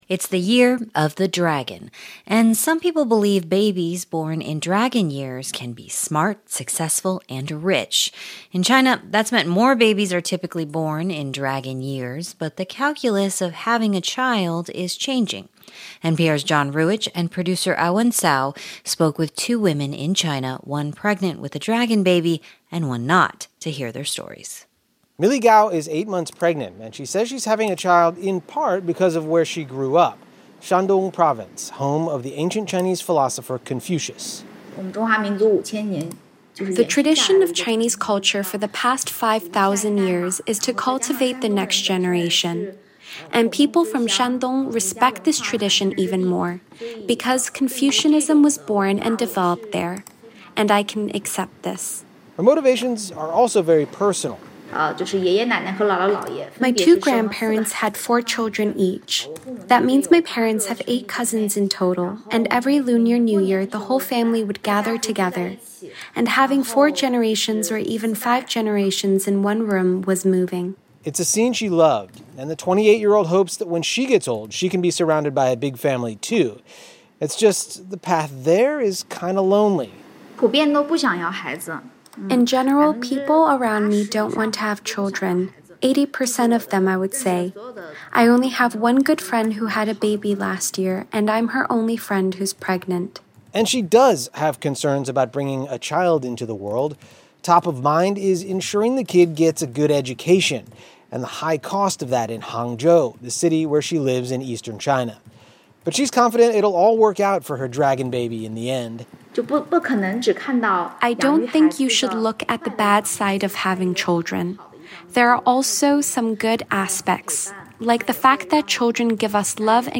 For many young Chinese these days, the calculus of having a child is changing. We hear from two women in China — one pregnant with a "dragon baby," and one not.